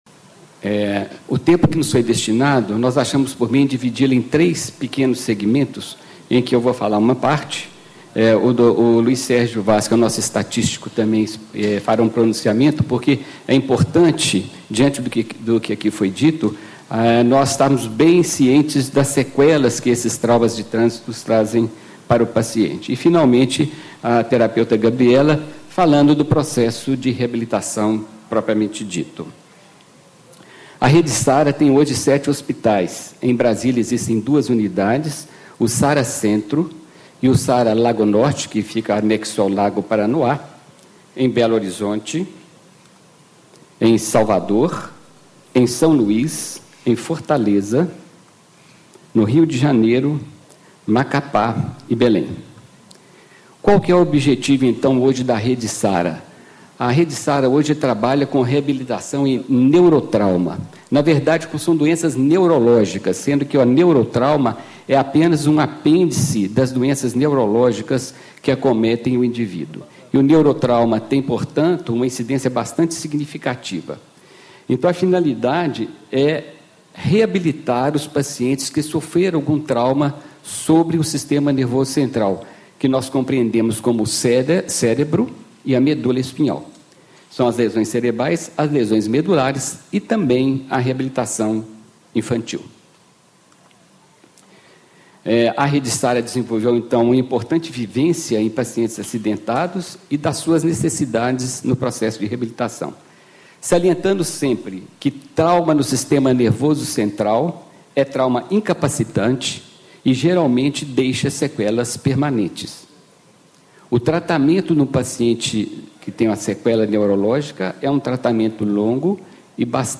Ciclo de Debates Siga Vivo - Pelo Fim da Violência no Trânsito
Discursos e Palestras